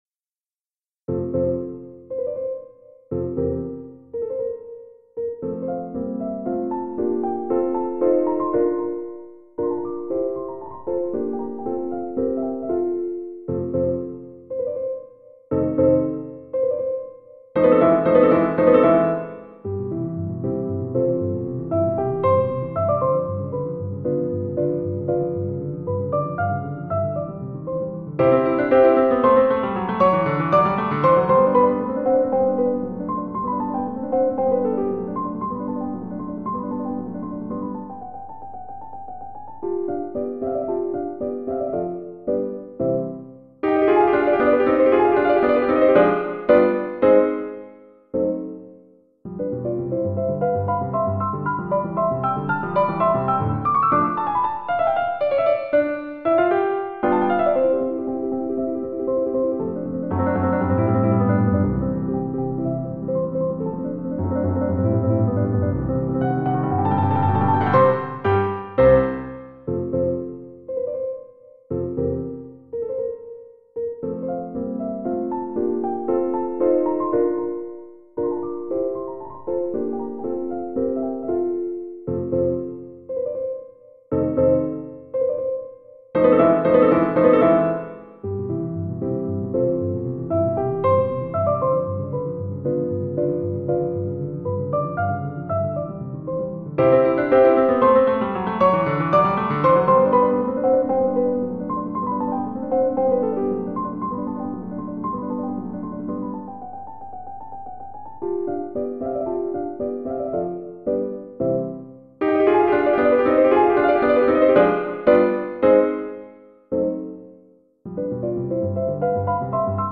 Saturday Noon Concert (5): Beethoven – Piano Sonata No. 5 in C-, Op.10, No.1
an audio rendering of his midi file